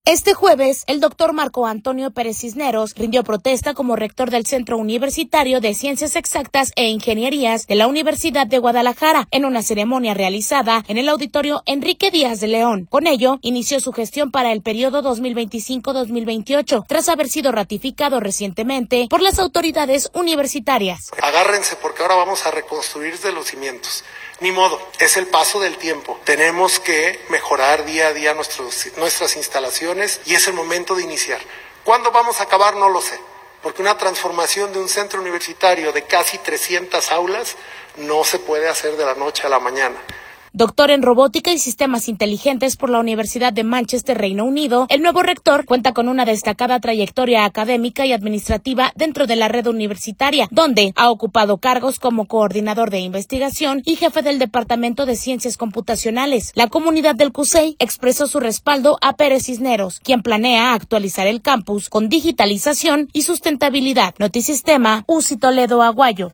Este jueves, el Doctor Marco Antonio Pérez Cisneros, rindió protesta como rector del Centro Universitario de Ciencias Exactas e Ingenierías (CUCEI) de la Universidad de Guadalajara, en una ceremonia realizada en el Auditorio Enrique Díaz de León.